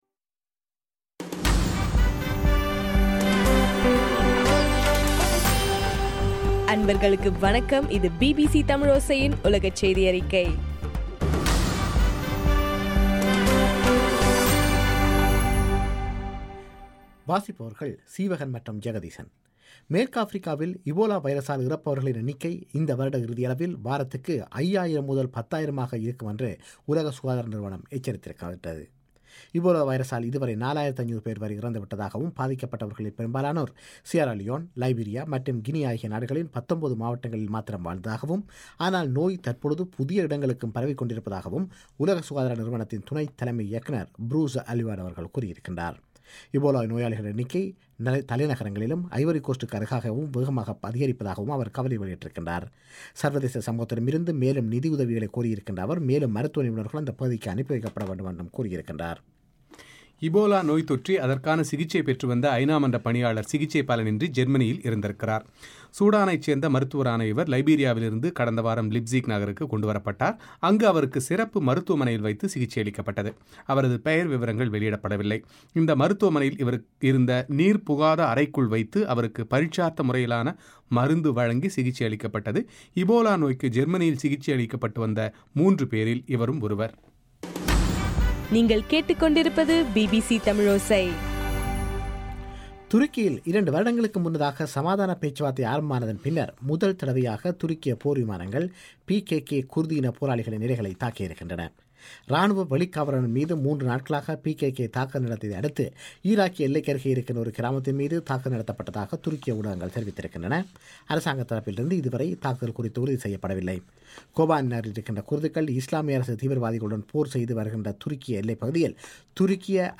அக்டோபர் 14 பிபிசியின் உலகச் செய்திகள்